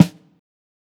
SNARE_KNIGHT_IN_SHINING.wav